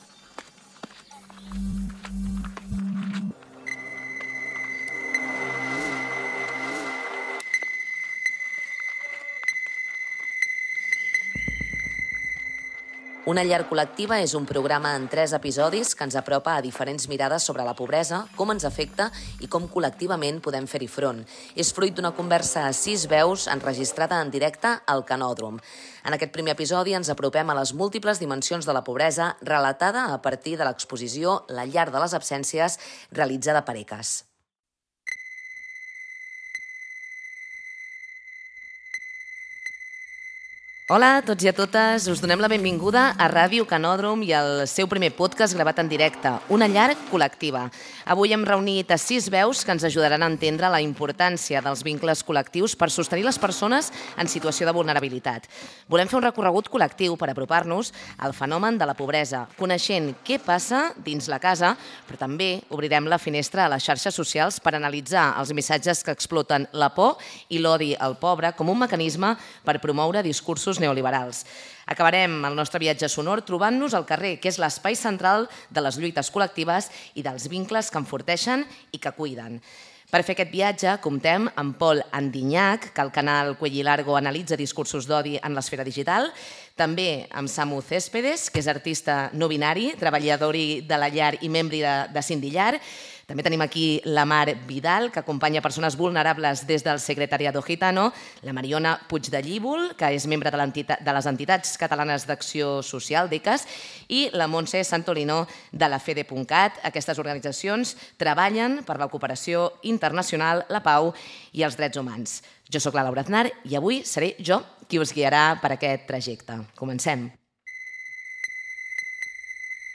Presentació del programa amb identificació de la ràdio, presentació dels invitats per tractar el tema de la importància dels llaços i de les estructures públiques per a sostenir les persones en situació de vulnerabilitat a les ciutats